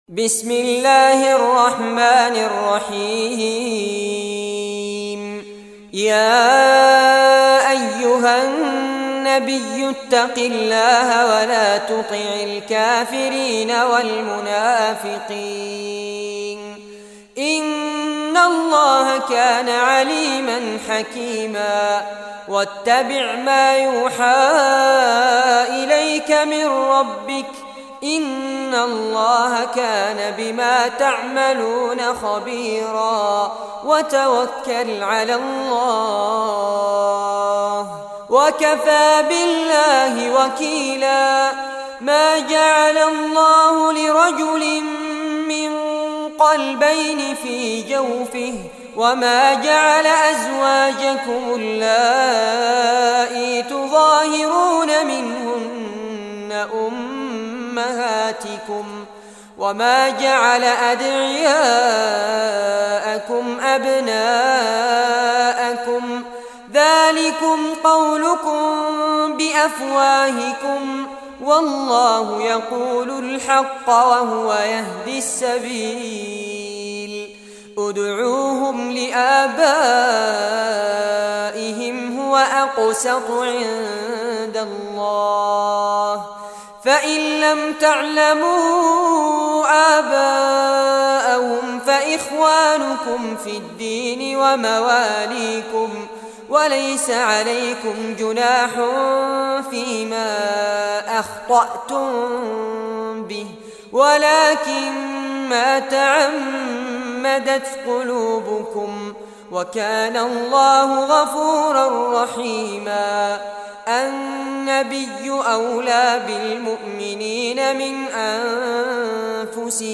Surah Al-Ahzab Recitation by Fares Abbad
Surah Al-Ahzab, listen or play online mp3 tilawat / recitation in Arabic in the beautiful voice of Sheikh Fares Abbad.